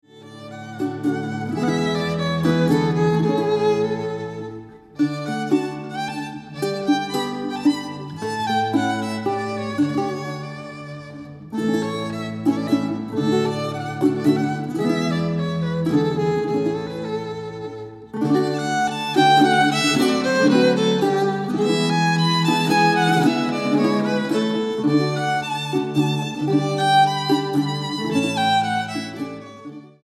soprano, flauta, percusiones
violín, violín piccolo
guitarra barroca, laúd, mandolina, charango, viola de gamba